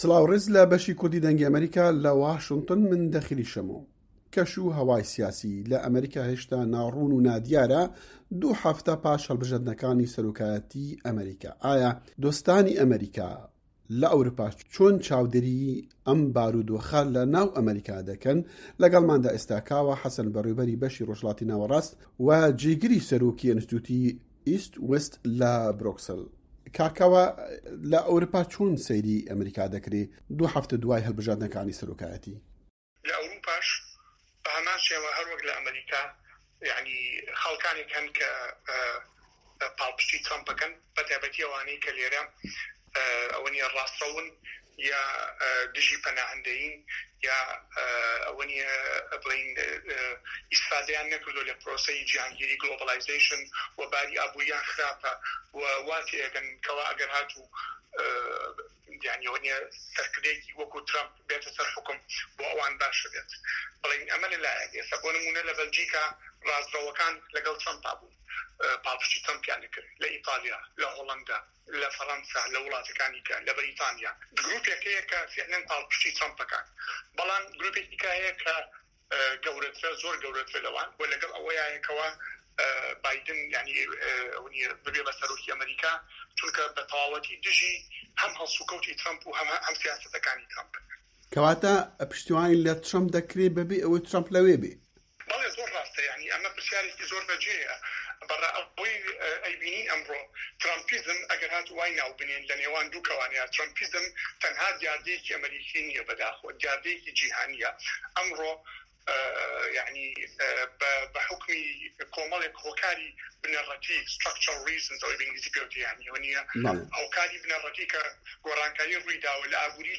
دەقی وتووێژەکە